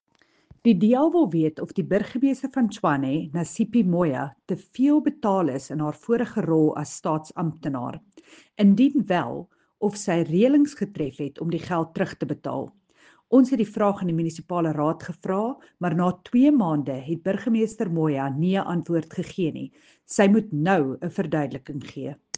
Afrikaans soundbites by Cllr Jacqui Uys